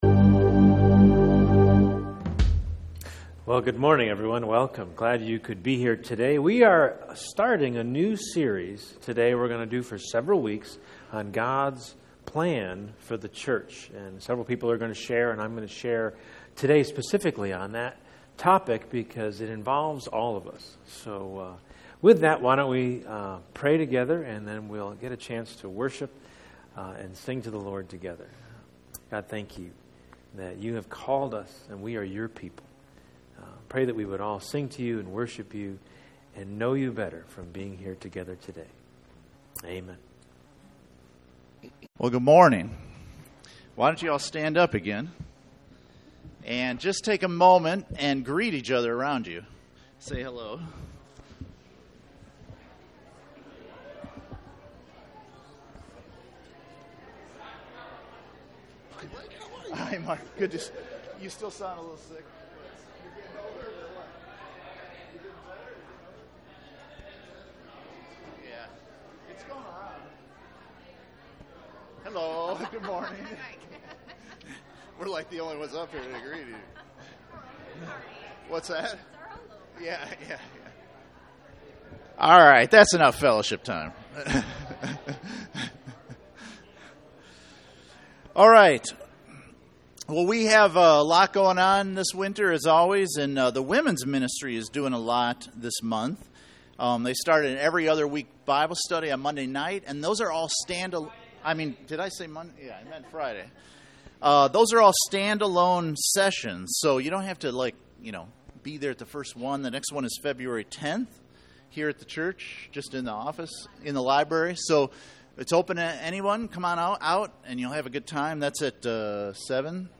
God's Plan for the Church Service Type: Sunday Morning %todo_render% « Pastor’s Heart for the Church